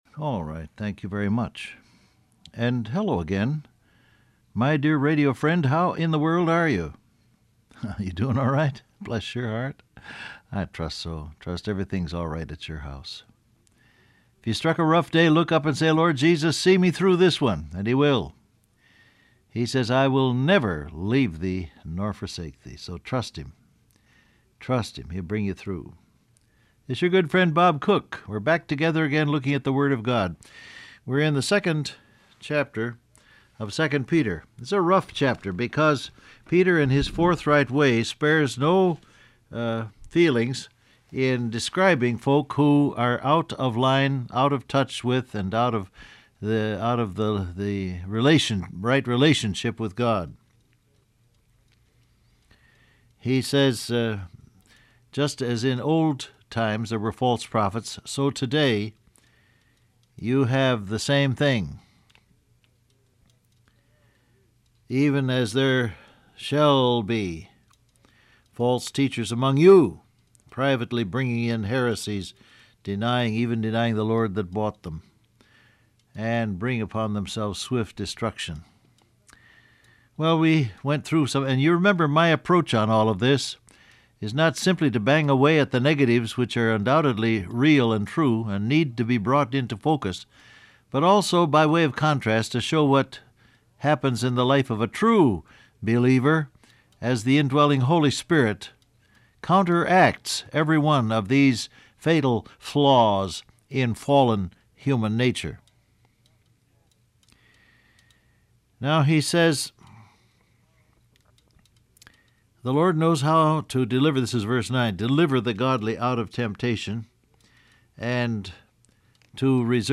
Download Audio Print Broadcast #7243 Scripture: 2 Peter 2:9-10 , Matthew 7:21-23, Romans 8:1 Topics: Sin , Lifestyle , Self Willed , Desires Of The Flesh Transcript Facebook Twitter WhatsApp Alright.